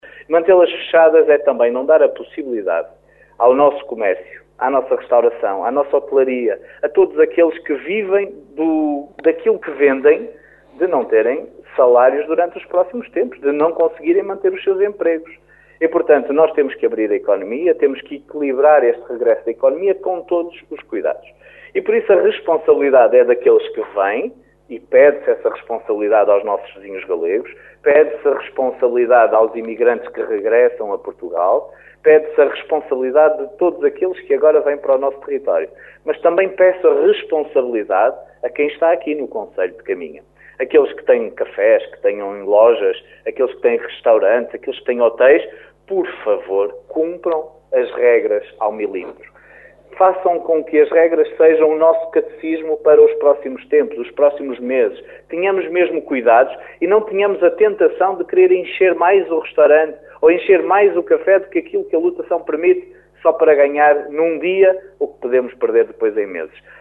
Manter fechadas as fronteiras significa segundo o presidente da autarquia caminhense, não dar oportunidade ao comércio, à restauração e à hotelaria que a continuarem assim poderão não ter dinheiro para pagar salários.